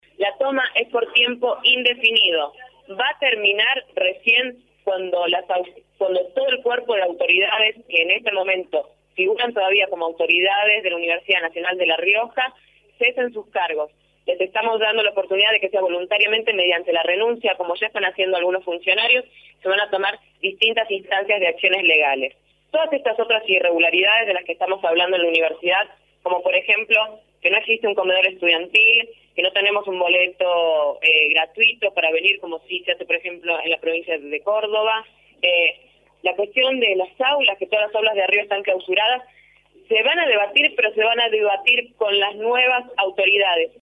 La conferencia de prensa de alumnos y profesores
conferencia.mp3